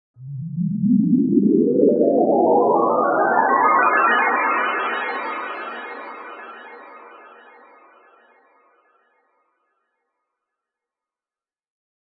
Cinematic Futuristic Sci-Fi Transition Sound Effect For Editing
High-Tech futuristic sound effect with smooth electronic tones and dynamic energy.
Genres: Sound Effects
Cinematic-futuristic-sci-fi-transition-sound-effect-for-editing.mp3